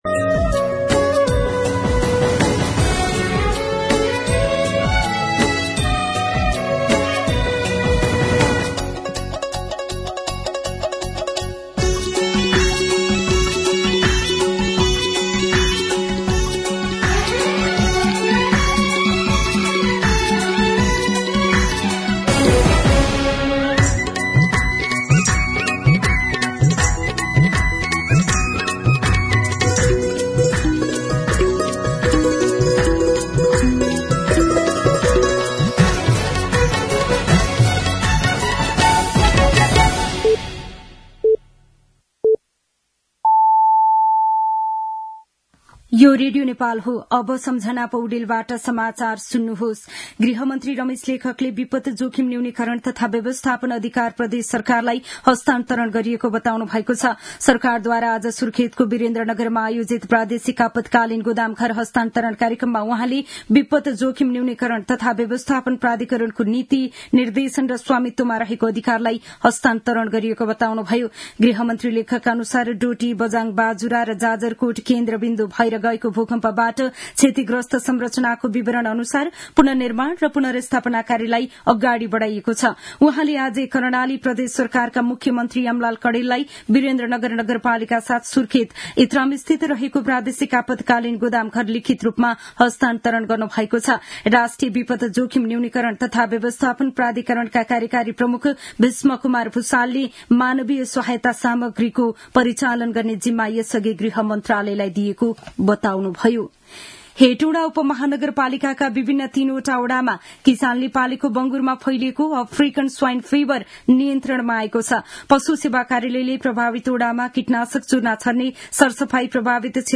दिउँसो १ बजेको नेपाली समाचार : २६ फागुन , २०८१